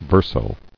[ver·so]